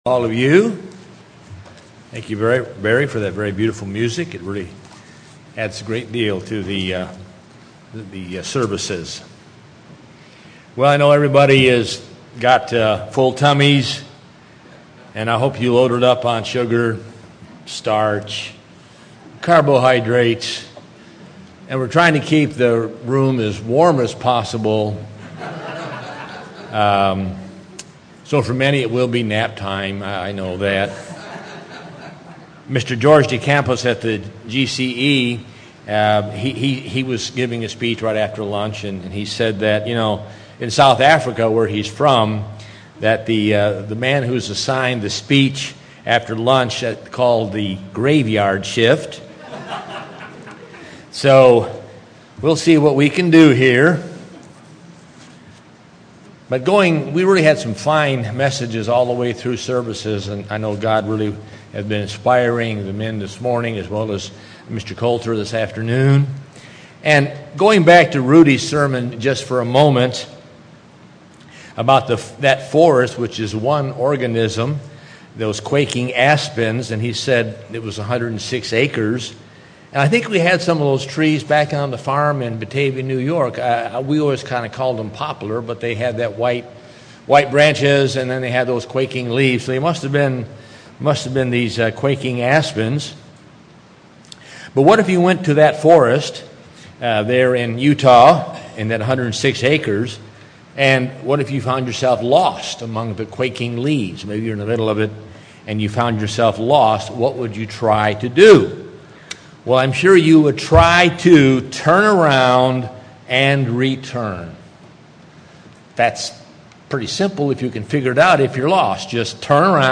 This sermon will cover two lessons we learn from Pentecost. The first is that Pentecost pictures a return to God through repentance and the giving of the Holy Spirit. The second is that because we have been given the Holy Spirit, we are to give to others what God has taught us.